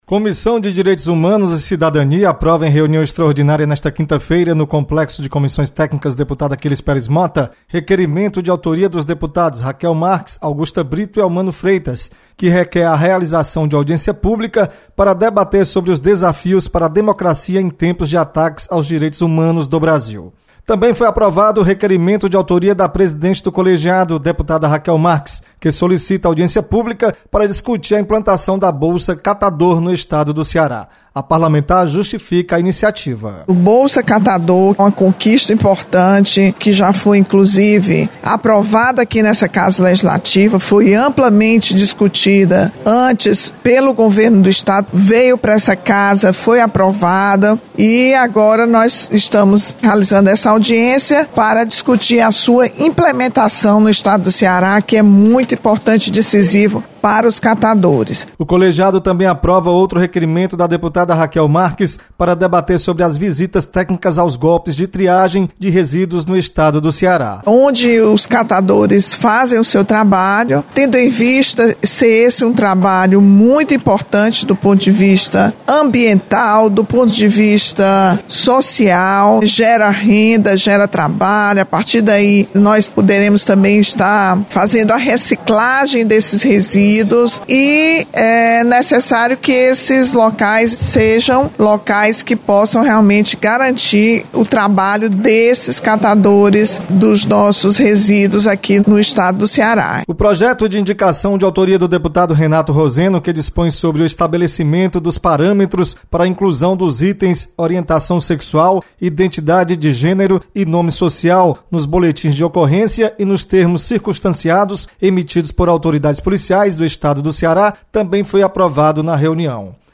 Comissão de Direitos Humanos realiza reunião esta quinta-feira. Repórter